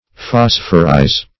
Phosphorize \Phos"phor*ize\,